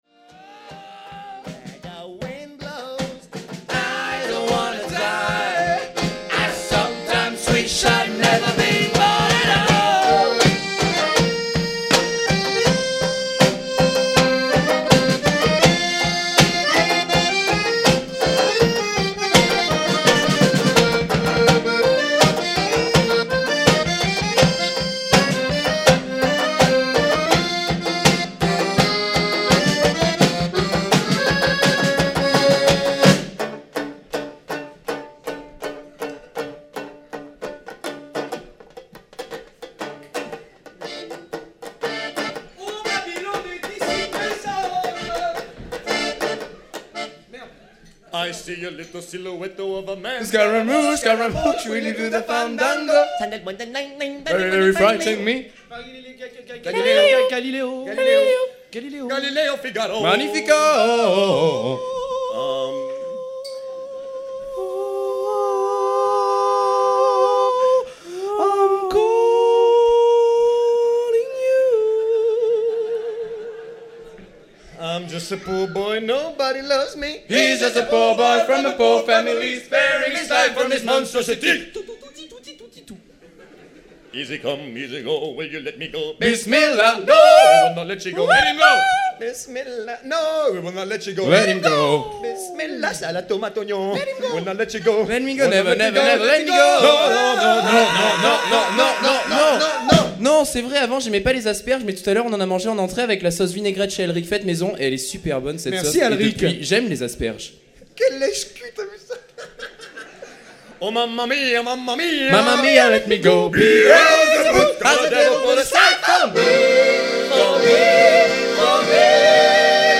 Live (07/05/05), faux et avec des pins !